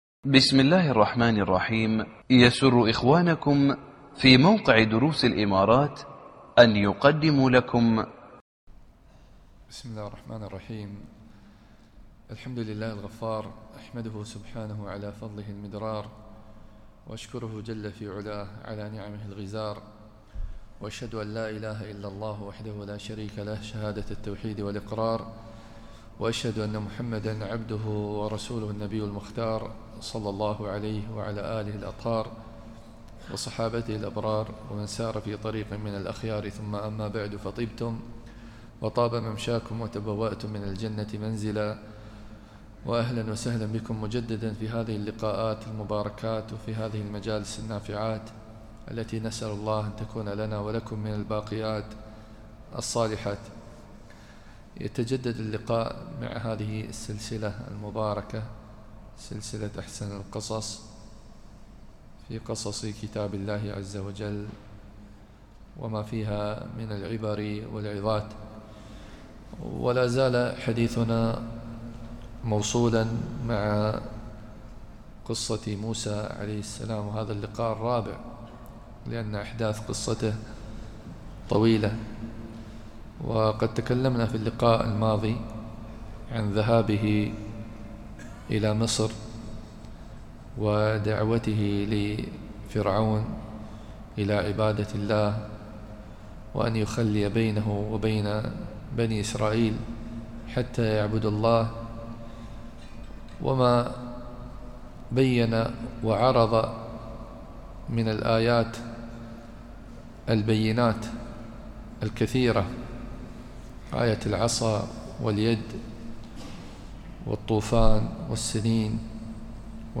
الدرس الخامس من سورة الواقعة 75 إلى الحشر 7